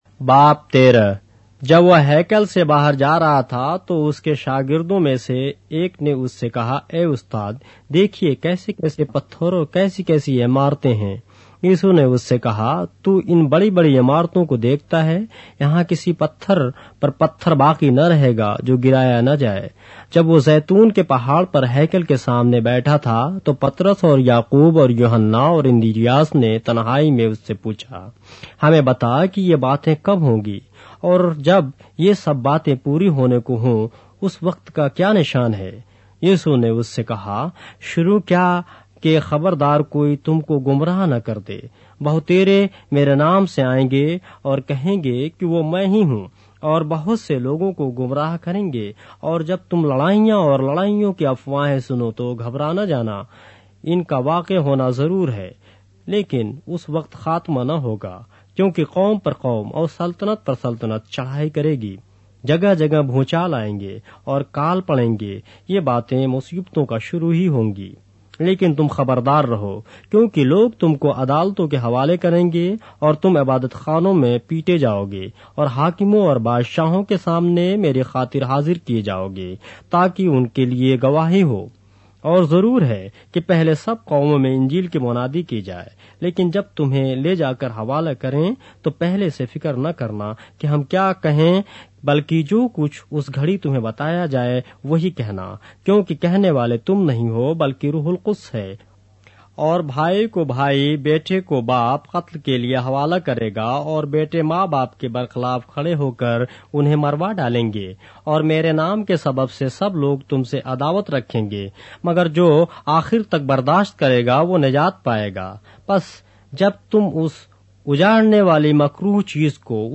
اردو بائبل کے باب - آڈیو روایت کے ساتھ - Mark, chapter 13 of the Holy Bible in Urdu